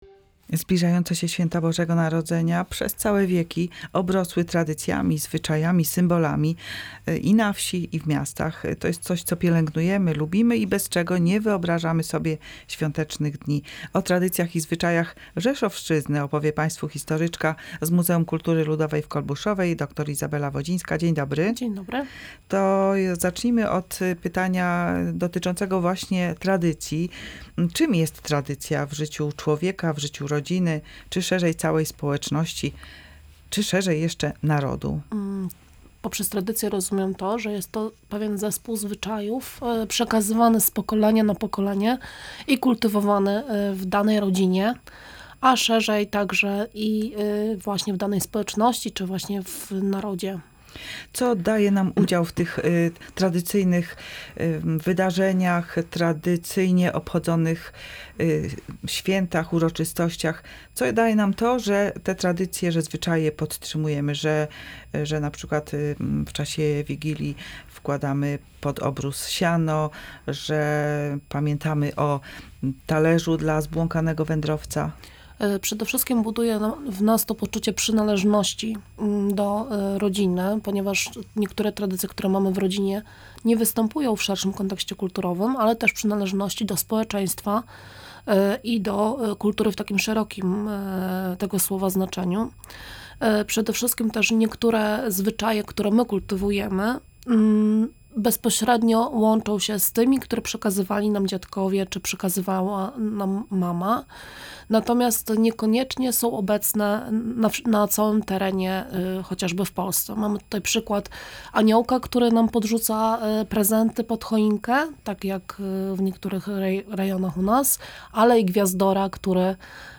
Mieliśmy okazję porozmawiać z etnografką na temat obyczajów staropolskich, występujących w święta.